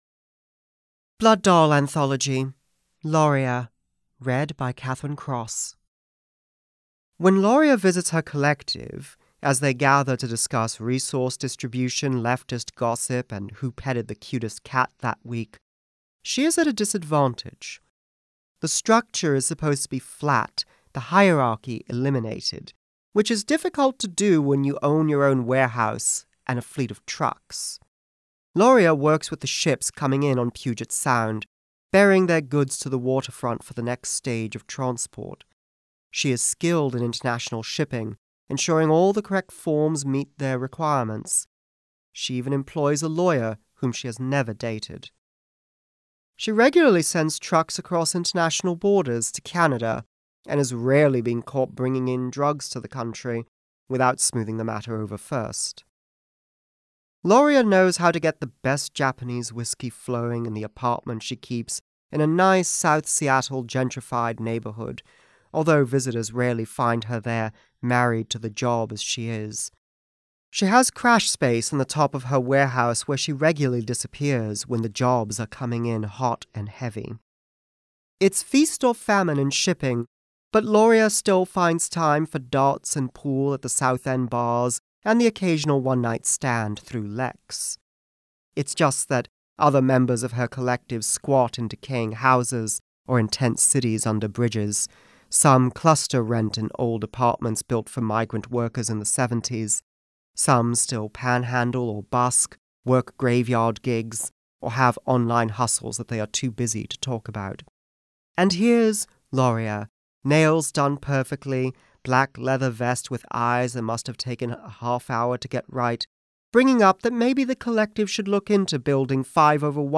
A series of vignettes about the characters in Vampire:The Masquerade: Blood Doll, read by their actors.